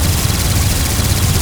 GUNAuto_Plasmid Machinegun C Loop_SFRMS_SCIWPNS.wav